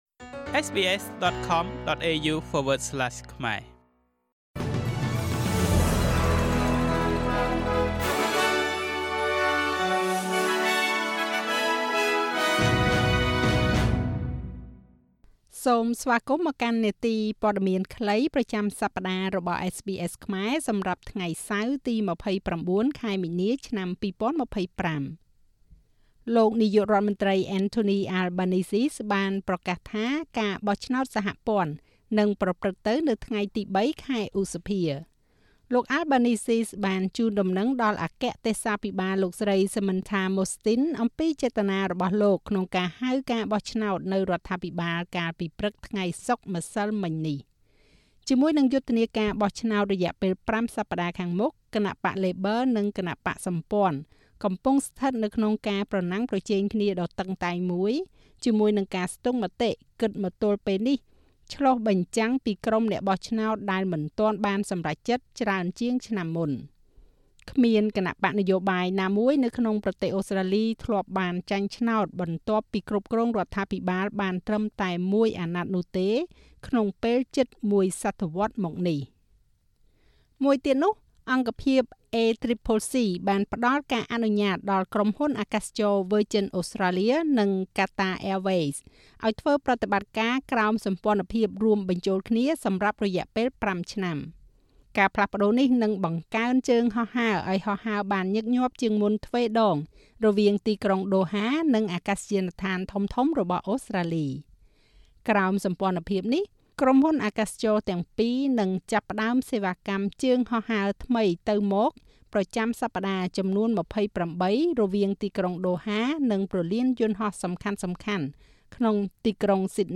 នាទីព័ត៌មានខ្លីប្រចាំសប្តាហ៍របស់SBSខ្មែរ សម្រាប់ថ្ងៃសៅរ៍ ទី២៩ ខែមីនា ឆ្នាំ២០២៥